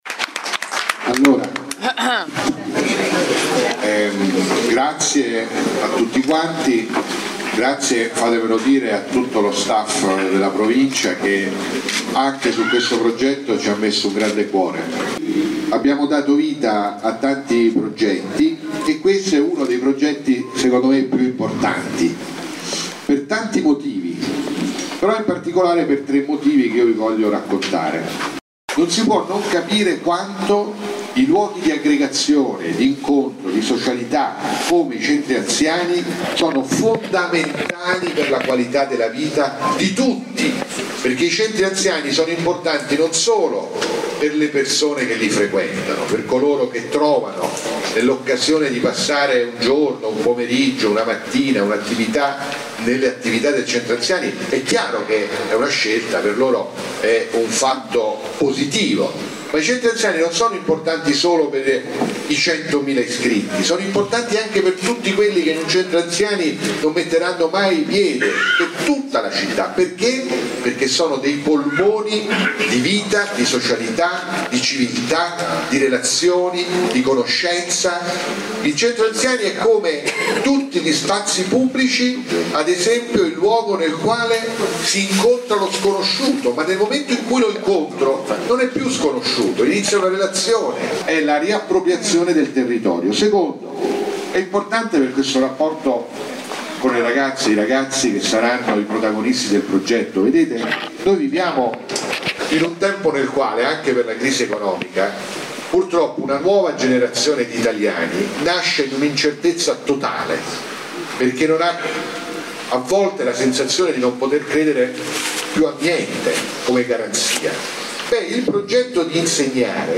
TEO_intervento_zingaretti.mp3